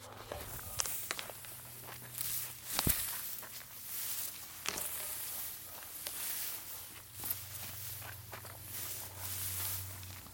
描述：鸟儿歌唱
Tag: 鸟鸣声 气氛 户外 线索 自然 现场录音